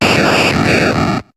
Cri de Voltorbe dans Pokémon HOME.